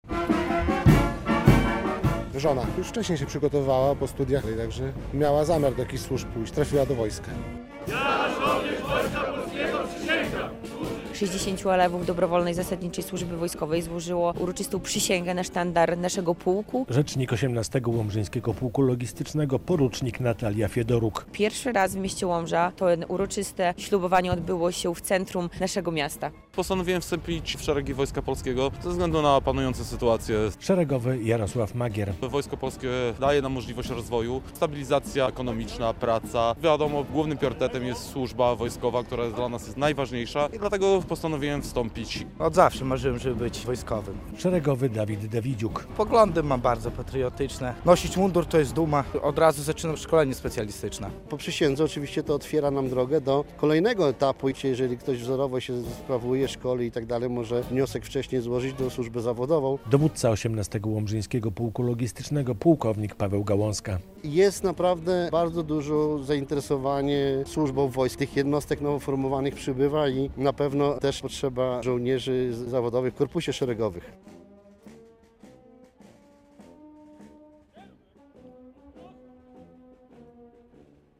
60 nowych żołnierzy złożyło w Łomży przysięgę - relacja
W tym uroczystym dniu nowym żołnierzom licznie towarzyszyli członkowie ich rodzin.